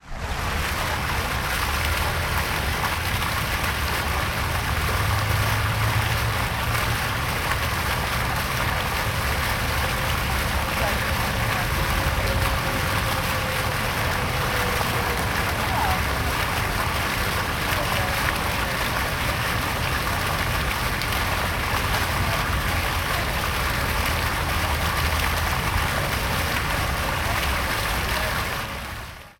Звуки фонтана
Фонтан журчит